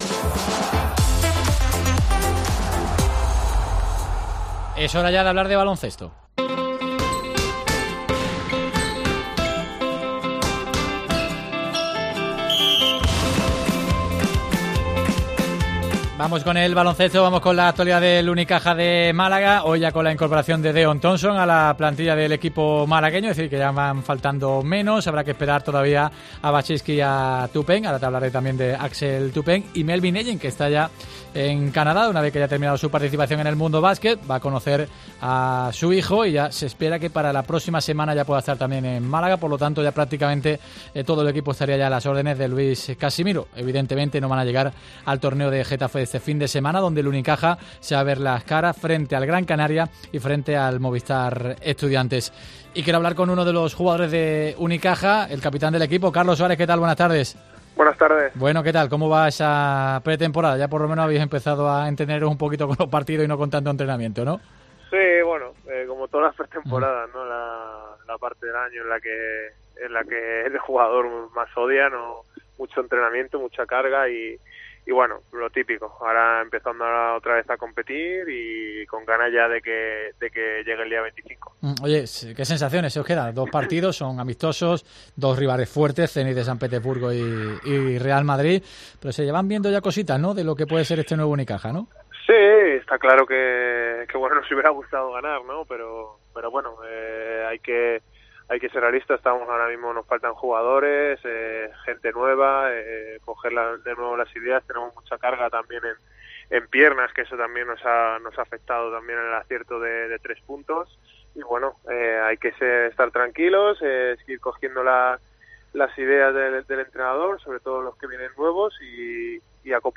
El capitán de Unicaja analizó en los micrófonos de Deportes COPE Málaga la pretemporada de Unicaja.